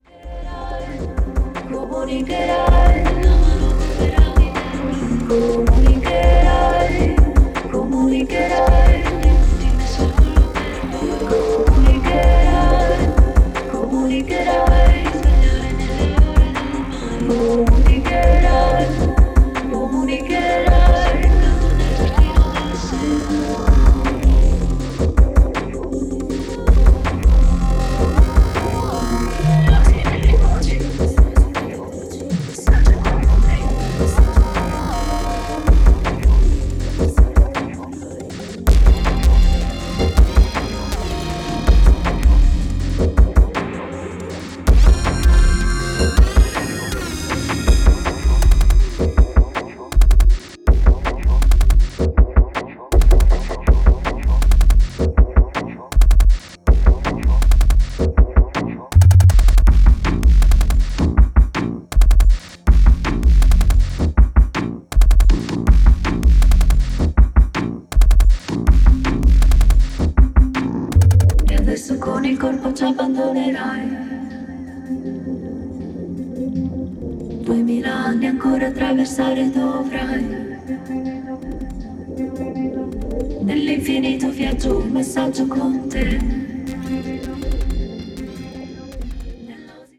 more rhythmical and functional tracks